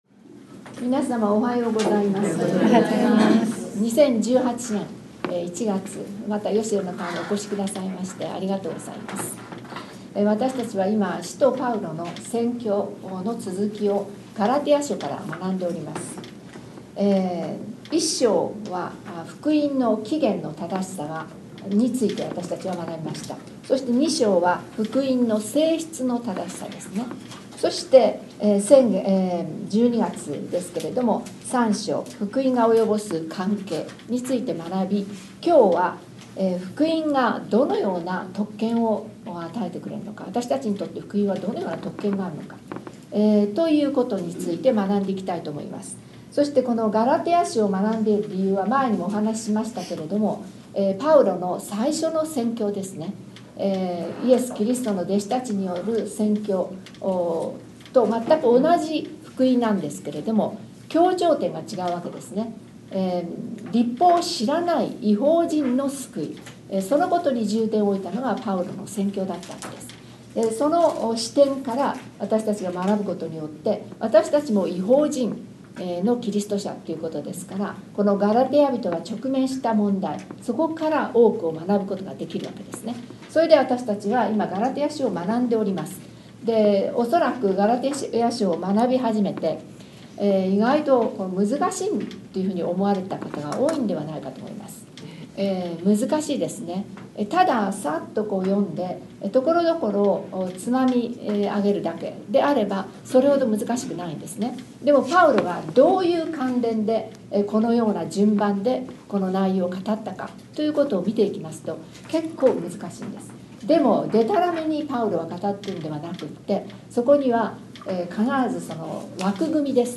1月16日に第36回ヨシェルの会が開催されました。出席者は19名でした。